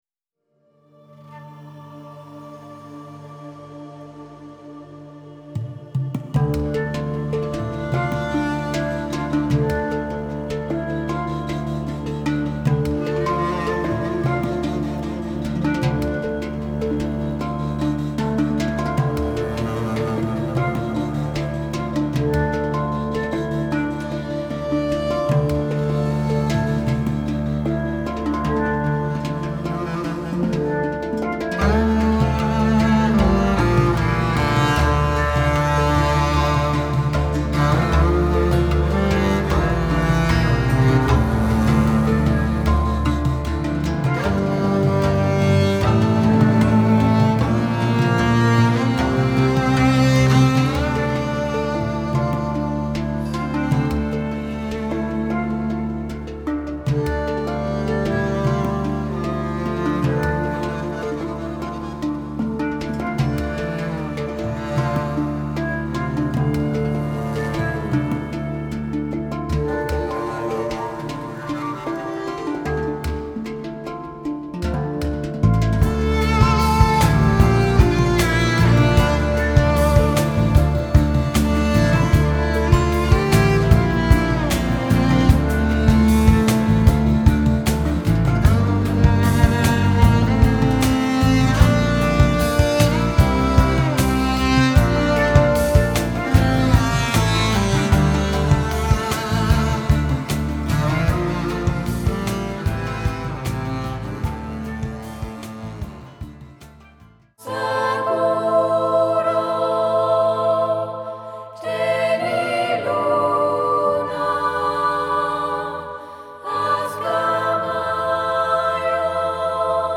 Gesang
Marimaba/Percussion
Akkordeon
Kontrabass
Klarinette
Gitarre/Marimaba/Hung
Audio-Mix